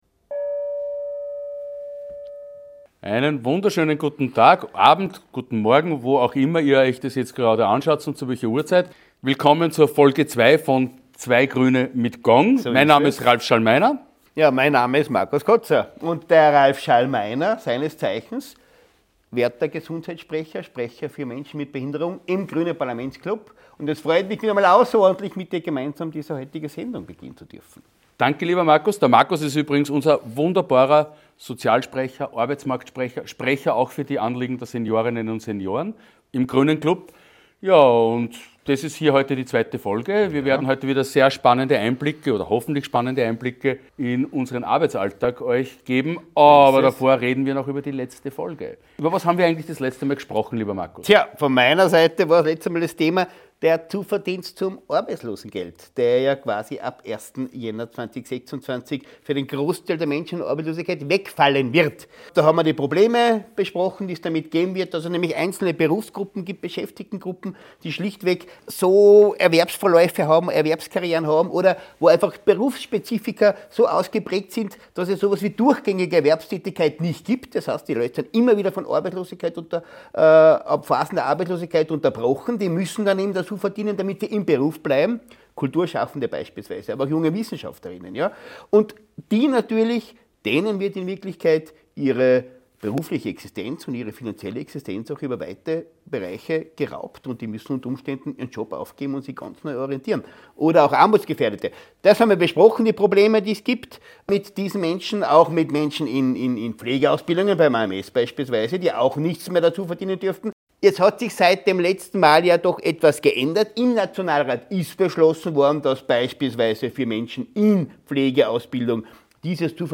Markus Koza und Ralph Schallmeiner erläutern das in gewohnt launiger Art.